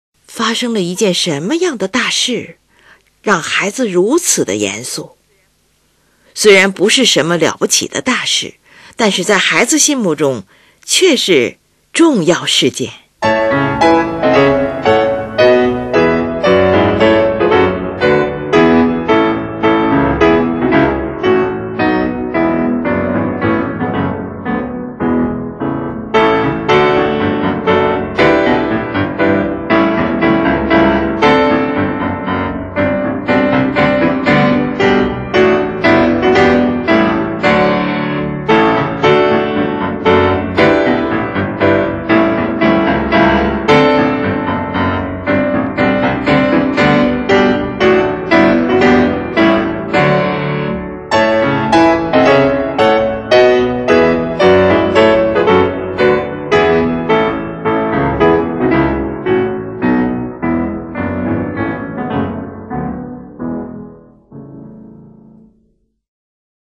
你听，乐曲的节奏规矩得近乎呆板，和声也是那么沉重，孩子那种令人发笑的一本正经的严肃神态，活现在我们面前。